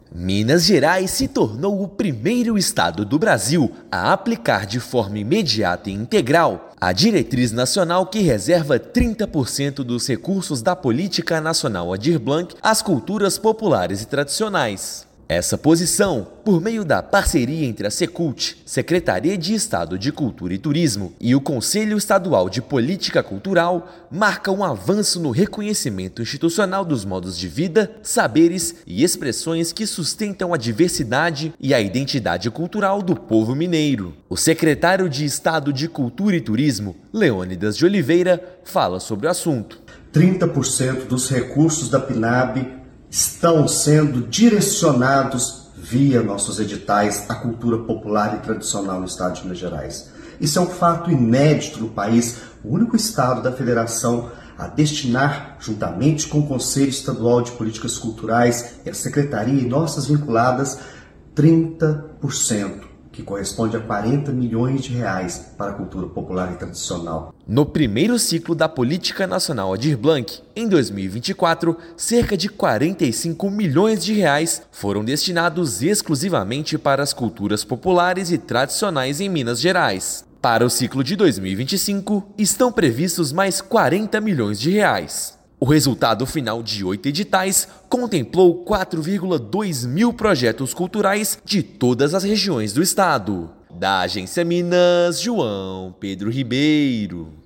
Estado investirá cerca de R$ 85 milhões no biênio 2024/2025, reconhecendo os saberes e fazeres que formam a alma da cultura mineira. Ouça matéria de rádio.